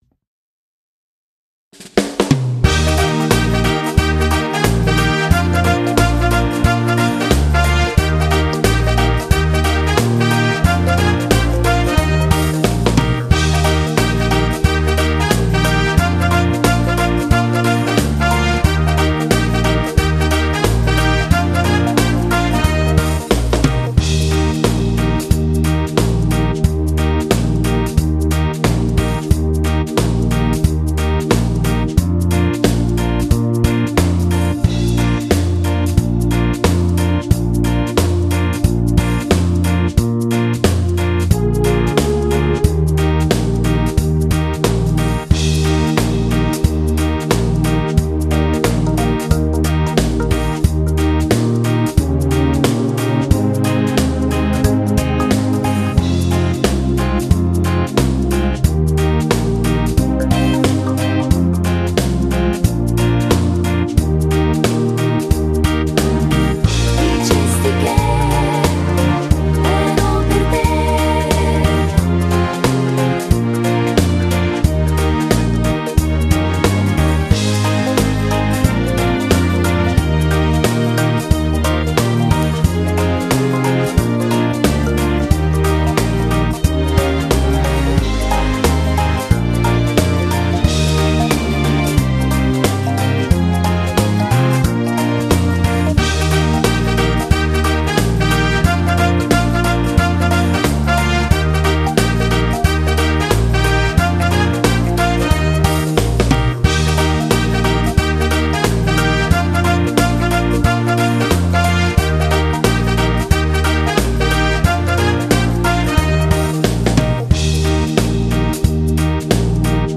Genere: Fox trot
Scarica la Base Mp3 (3,62 MB)